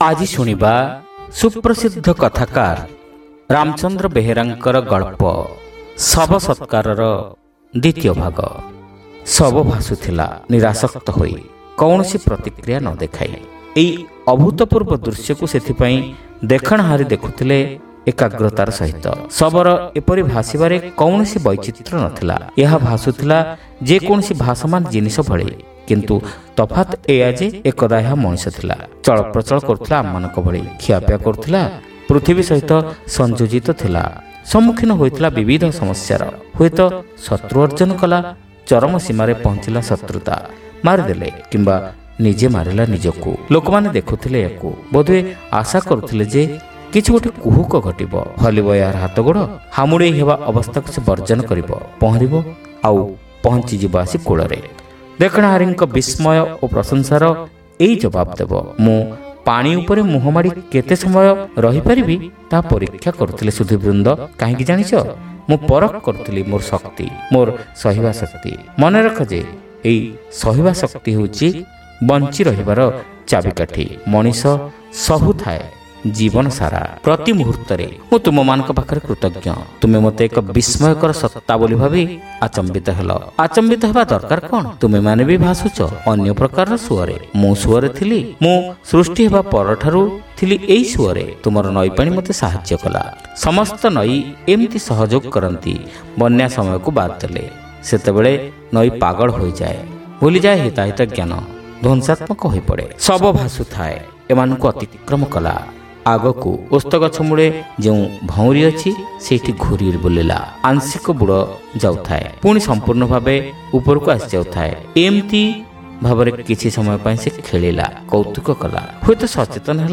Audio Story : Shaba Satkara (Part-2)